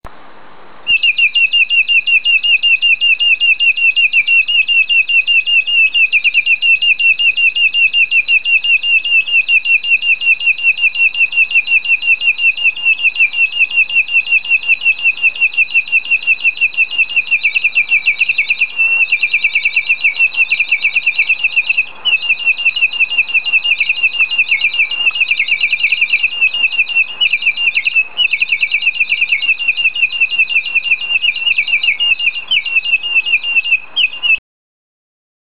Звуки разбойников
Свист соловья разбойника